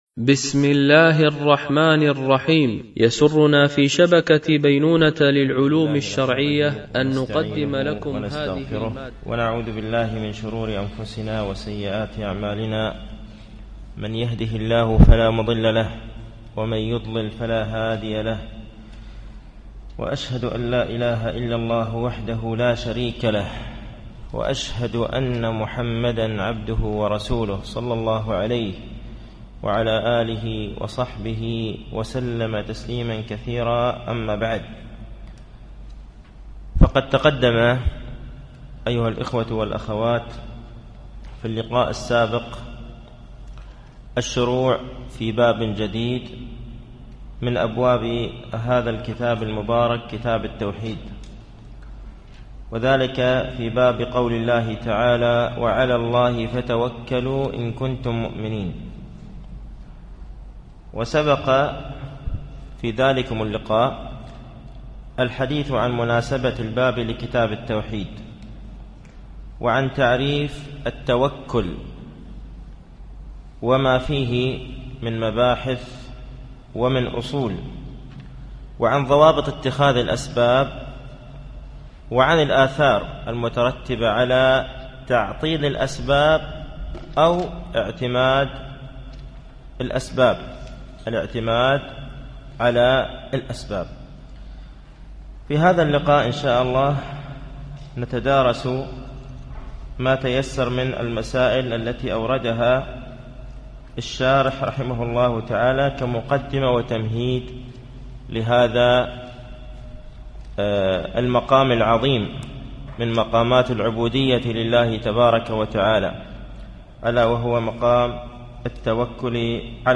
التعليق على القول المفيد على كتاب التوحيد ـ الدرس السادس بعد المئة